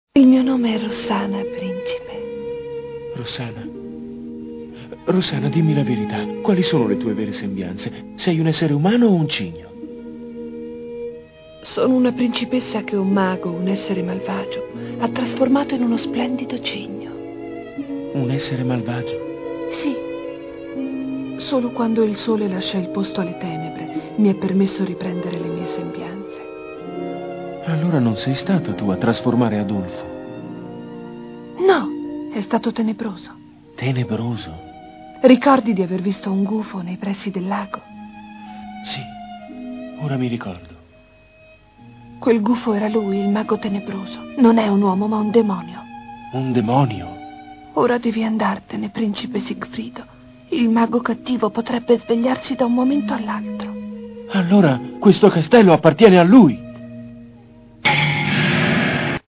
voce di Simona Izzo dal film d'animazione "Il lago dei cigni", in cui doppia Rossana.